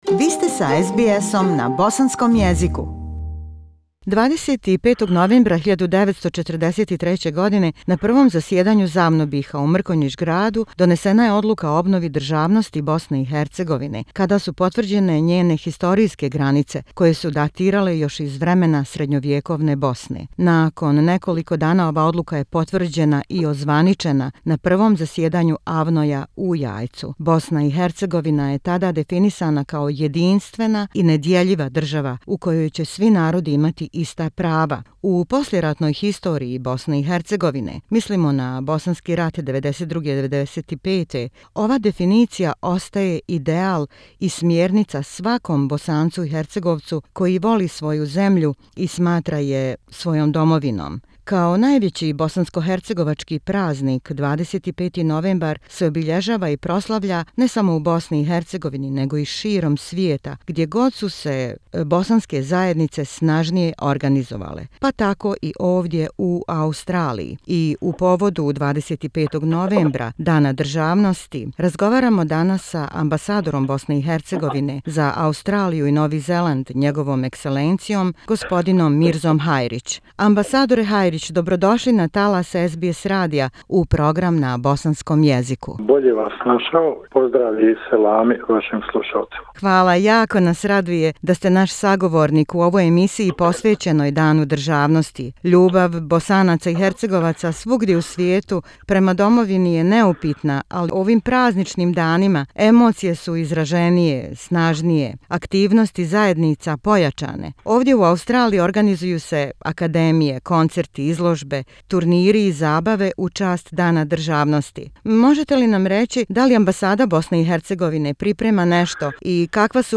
In the honour of the Bosnian National day- an interview with H.E. ambassador of Bosnia and Herzegovina, Mirza Hajric